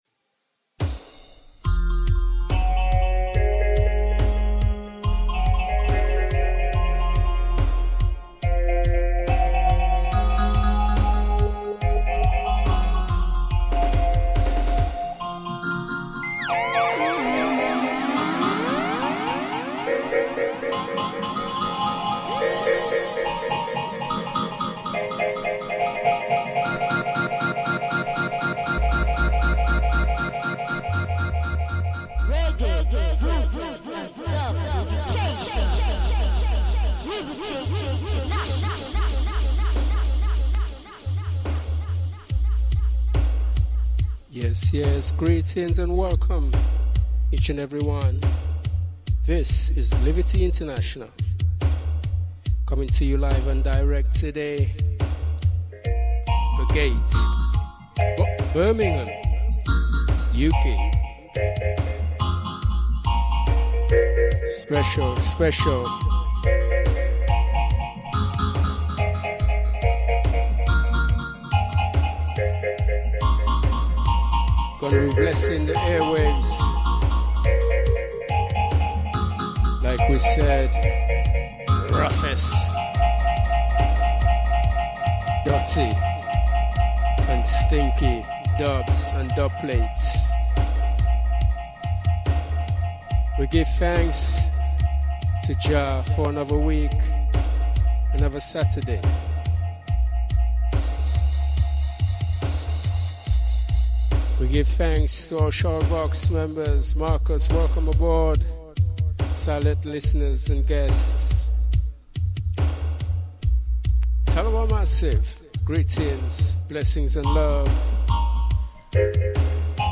LIVE & DIRECT from The GATE in Birmingham UK.